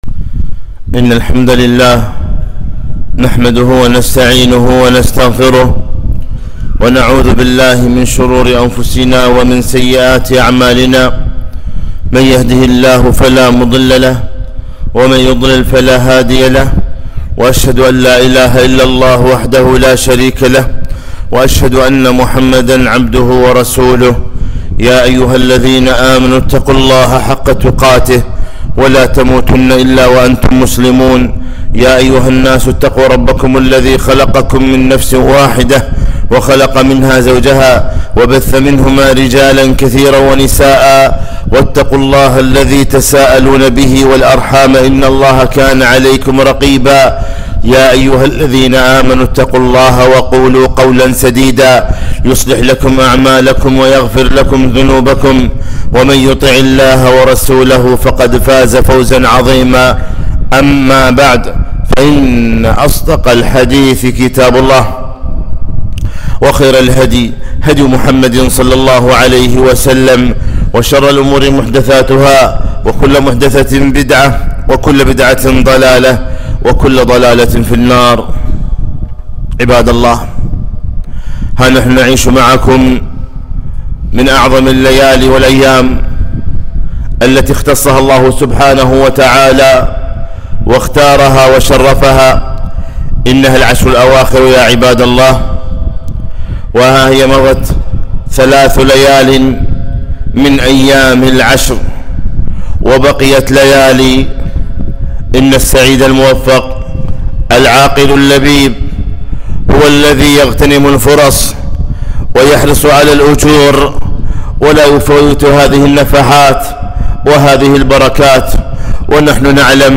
خطبة - اغتنموا ما بقي من رمضان